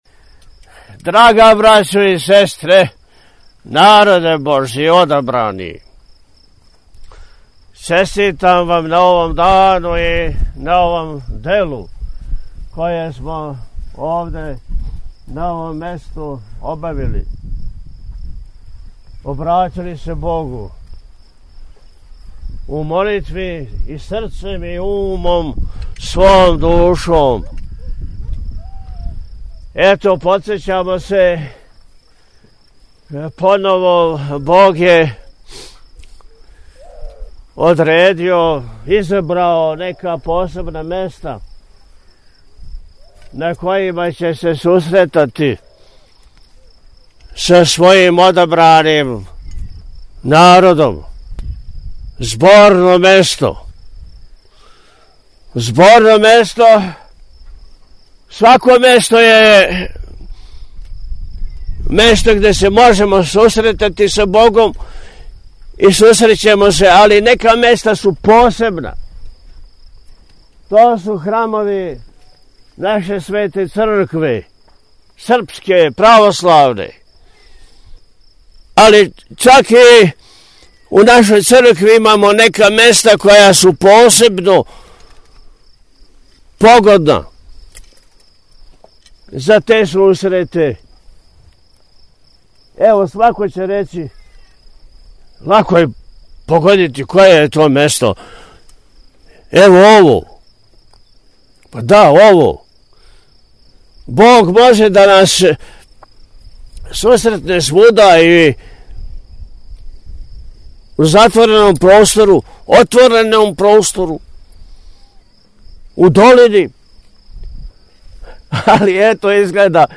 Након освећења и подизања крста Високопреосвећени се, видно одушевљен предивном природом села Врбова, на надморској висини од око 1200 метара, пригодном беседом обратио сабранима: – Бог је одредио, изабрао, нека посебна места на којима ће се сусретати са својим одабраним народом, зборно место.
Vrbovo-Beseda.mp3